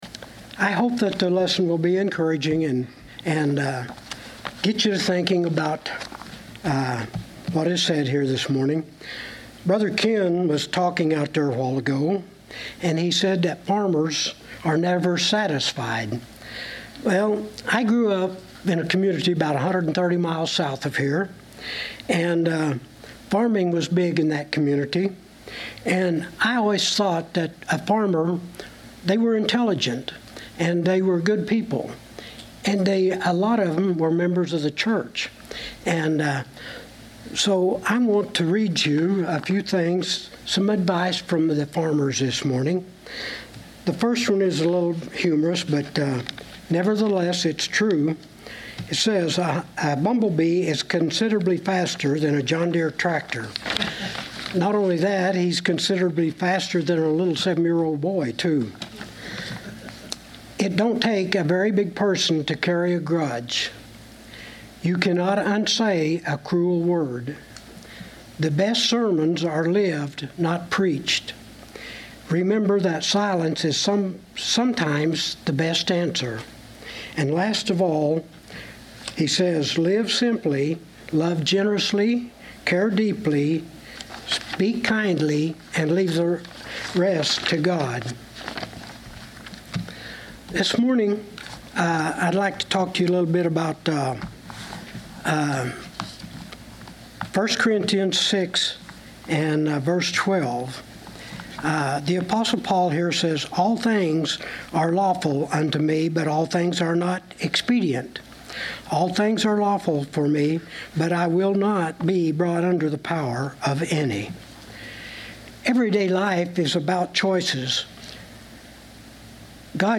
1 Corinthians 6:12 Service Type: Sunday 11:00 AM Topics: Christian Living « Written in Heaven